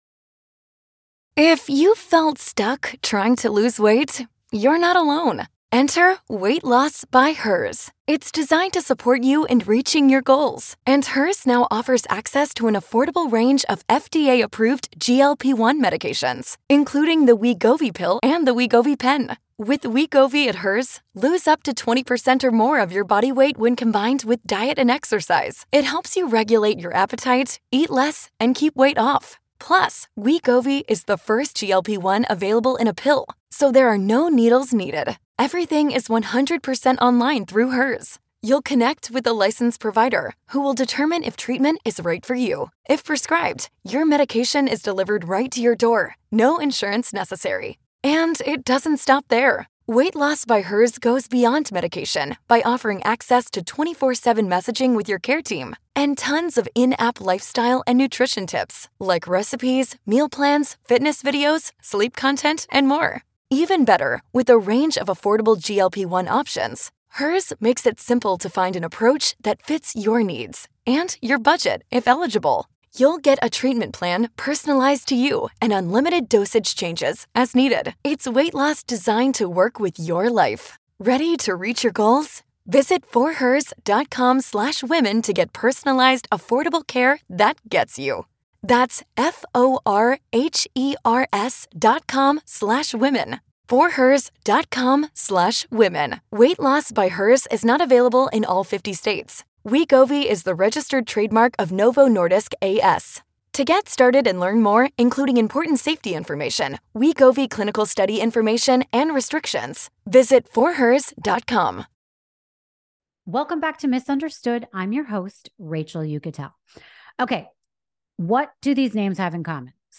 Mark Geragos is a force to be reckoned with in the courtroom and we got him to chat about all things happening right now in true crime. He gives us his insightful opinions on the evidence in the Idaho murder case of Brian Kohberger, whether cameras should be allowed in the courtroom for the upcoming Donald Trump trial and why he believes the new evidence brought forth in the Menendez Brothers case will help get his clients released from prison.